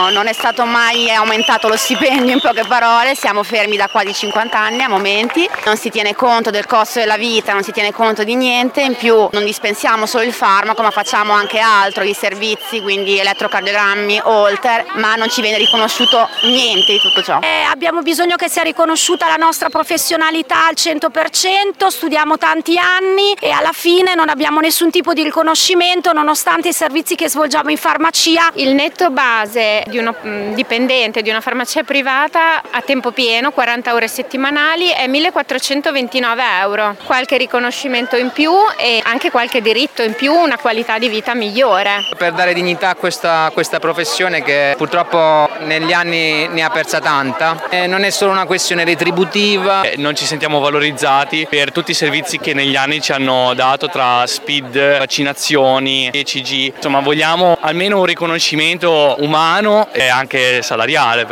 Per tutta la mattina si è svolto un presidio davanti alla sede di Federfarma.